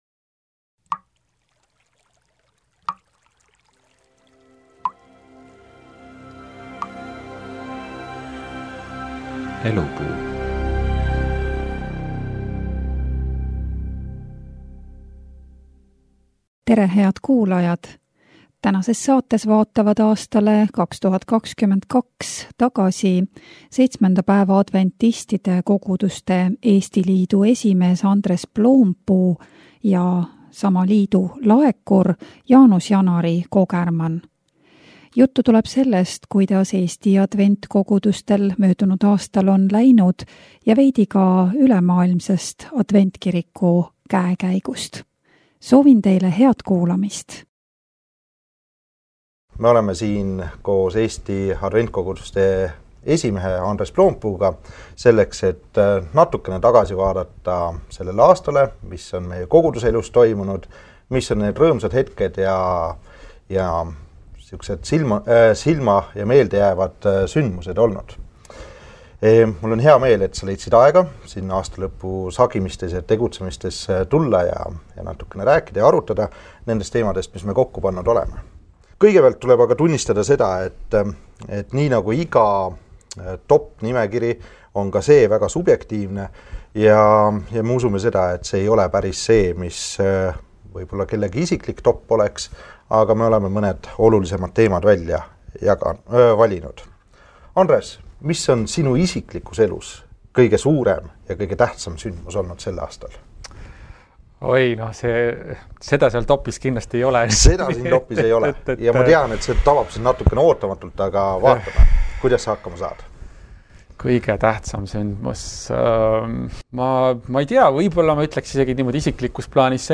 Pereraadio saade Elupuu